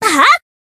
BA_V_Hanako_Battle_Shout_3.ogg